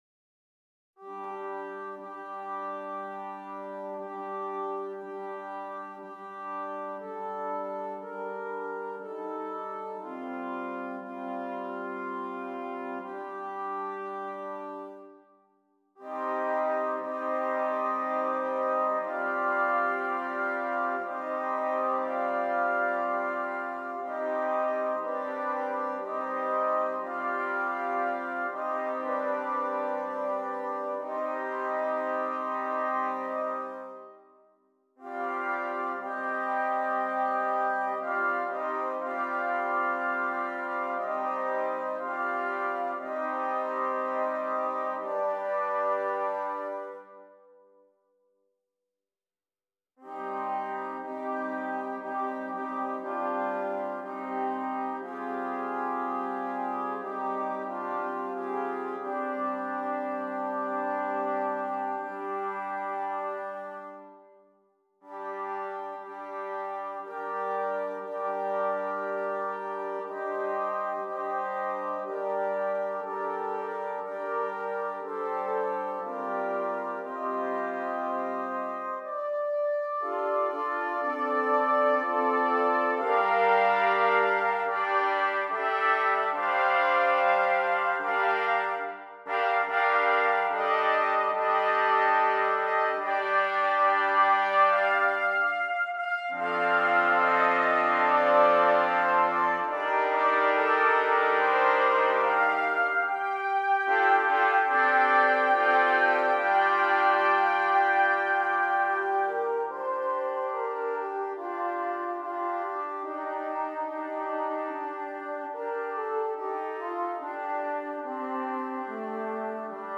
8 Trumpets